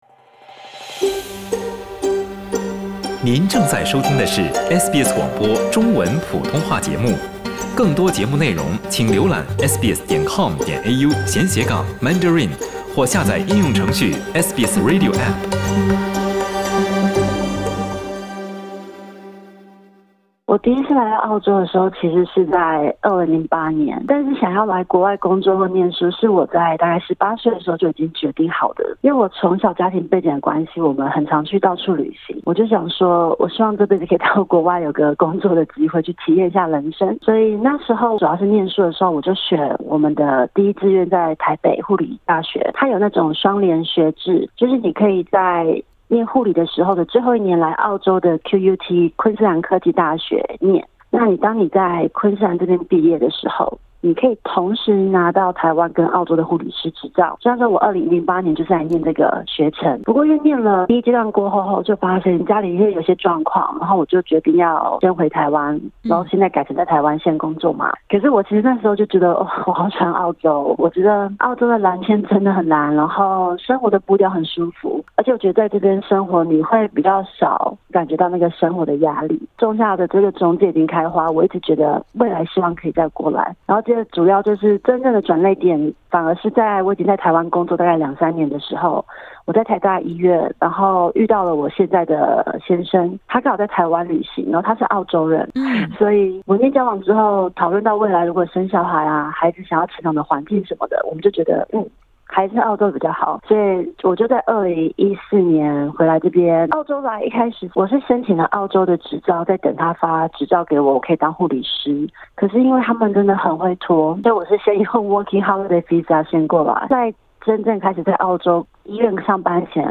Source: Supplied 欢迎点击图片，收听完整采访音频。